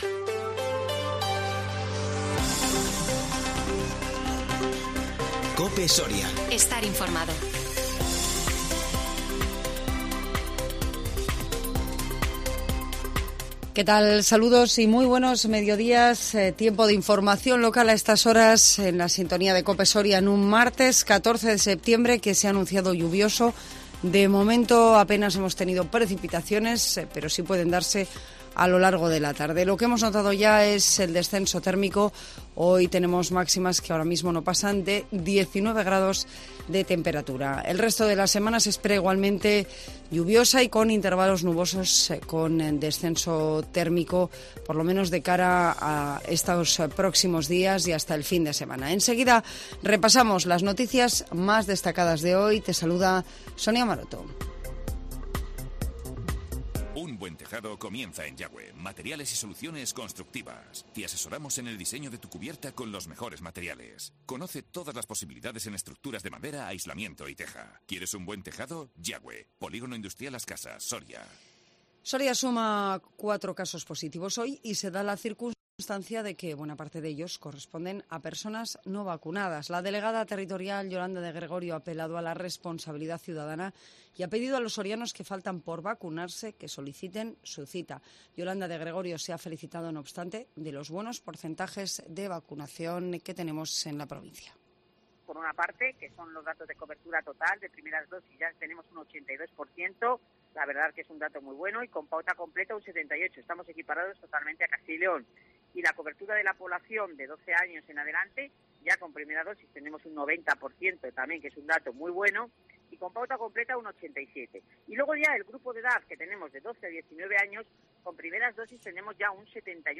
INFORMATIVO MEDIODÍA 14 SEPTIEMBRE 2021